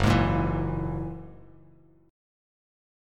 F#9 Chord
Listen to F#9 strummed